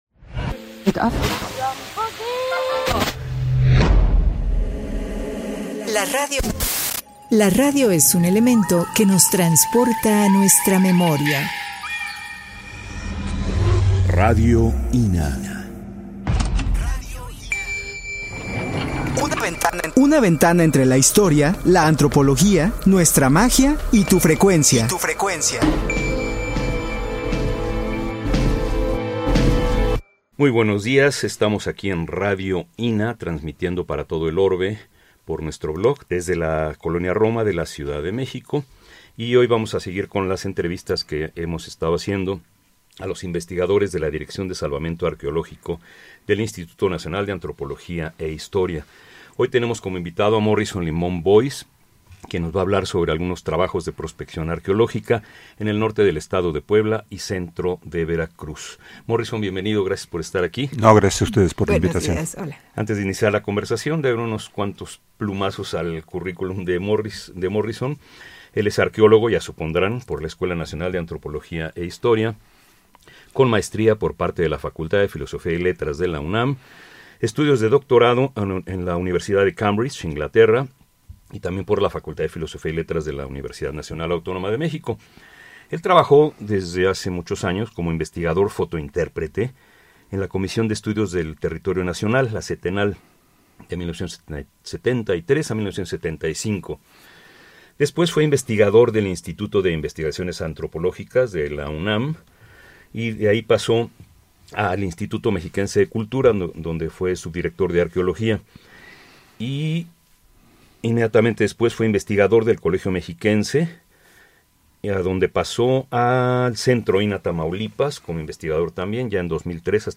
entrevista_2-Service File.mp3